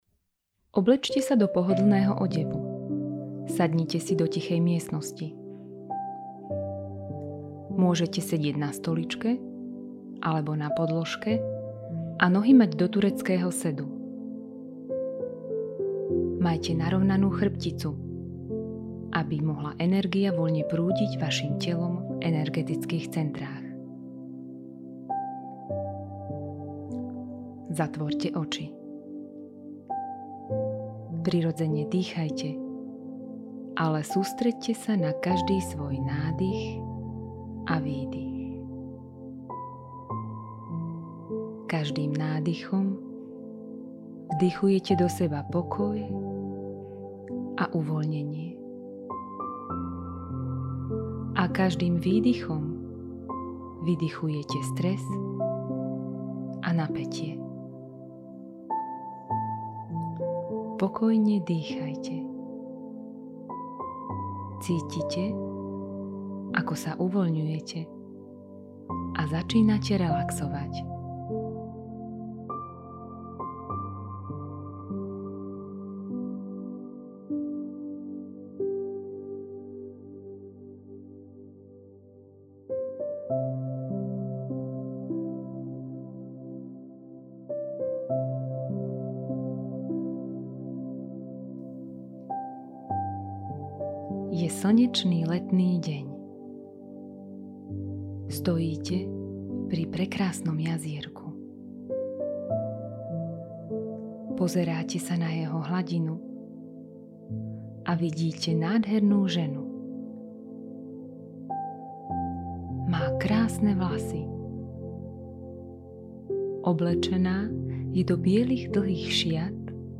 Meditačno-vizualizačné cvičenie SEBALÁSKA Nezabudni, pred meditovaním sa vycikaj, pohodlne sa obleč, nájdi si pokojné miesto a pohodlne sa usaď.
Meditacne-cvicenie-Sebalaska.mp3